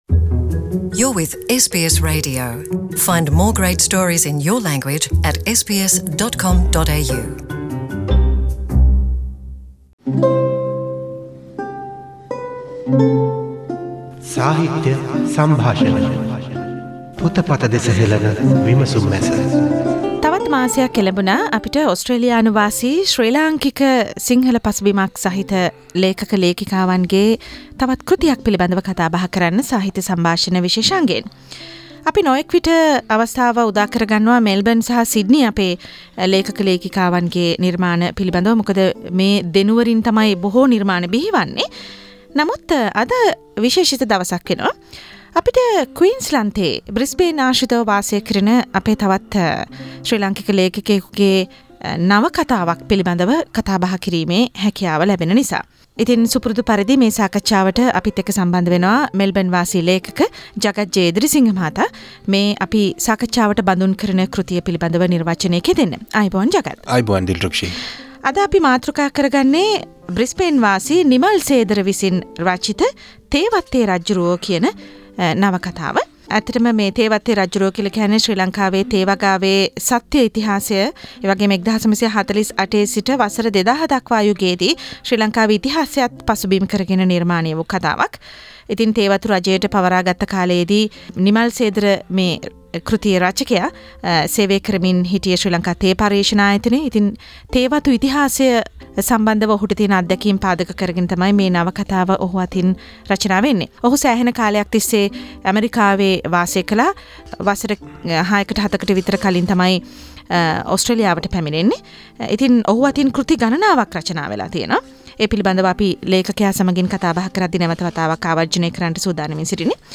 SBS සිංහල සේවය ගෙන ආ සාකච්චාව මෙම විෂේශාන්ගයෙන්